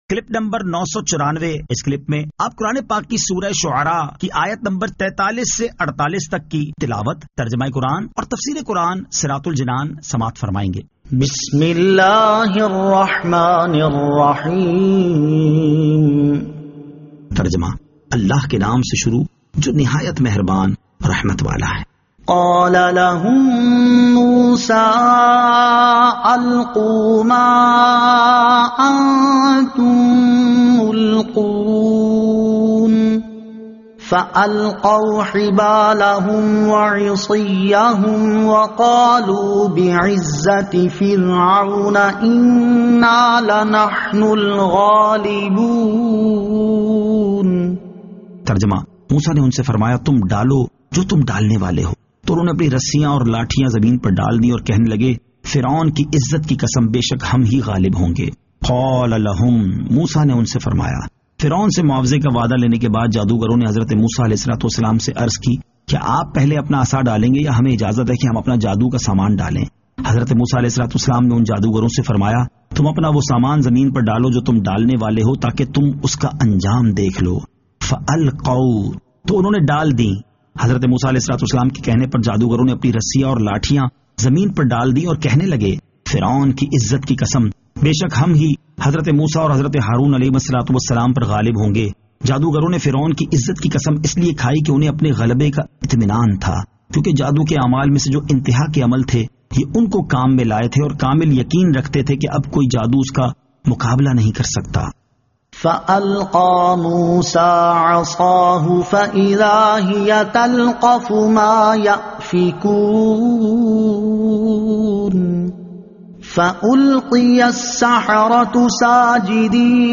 Surah Ash-Shu'ara 43 To 48 Tilawat , Tarjama , Tafseer